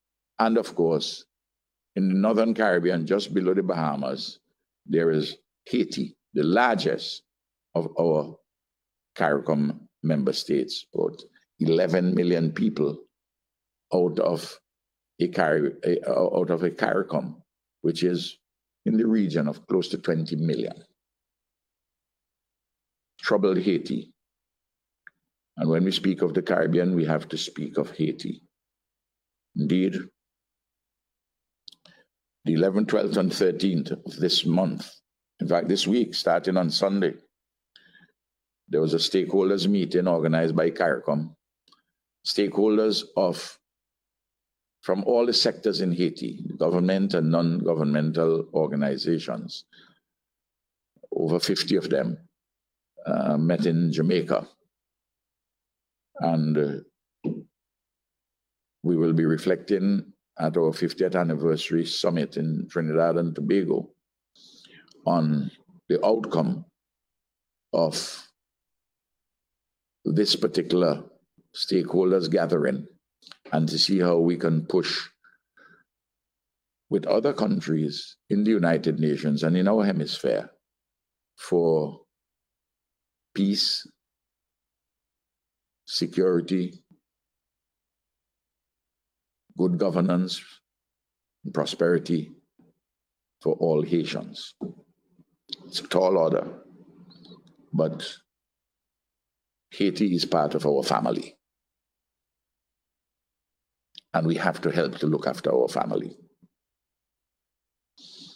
The Prime Minister raised the issue as he presented a lecture at the Caribbean Research Centre’s Heritage month celebration.